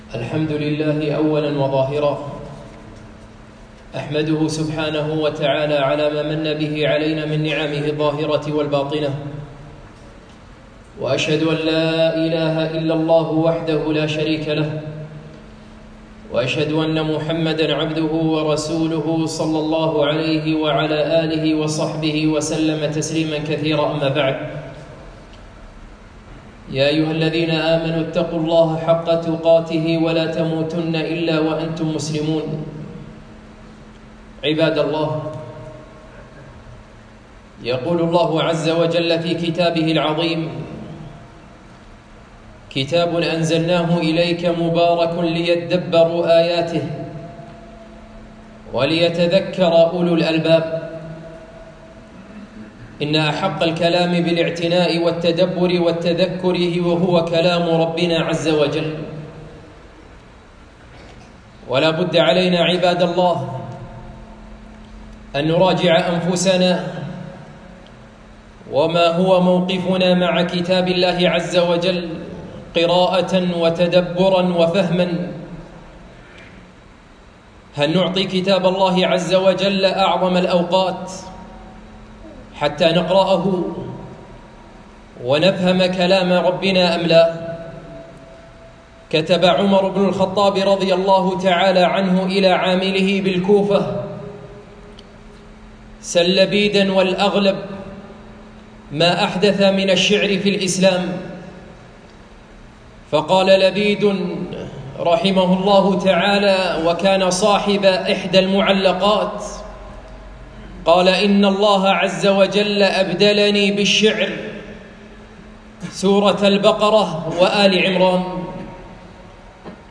خطبة - تفسير السبع المثاني